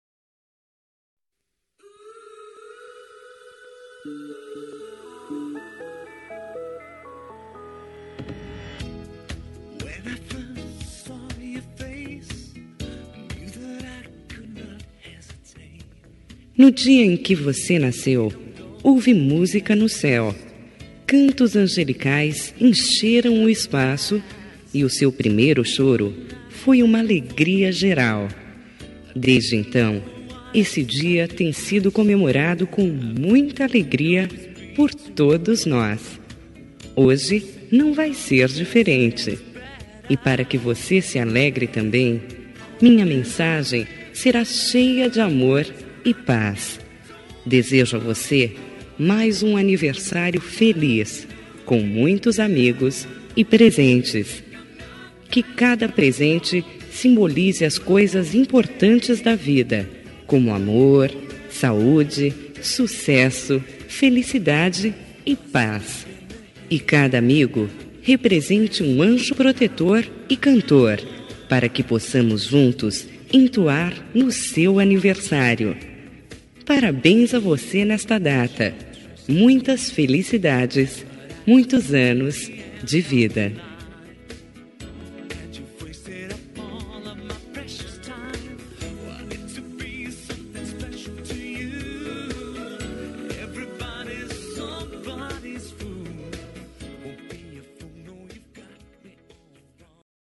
Aniversário de Pessoa Especial – Voz Feminina – Cód: 202218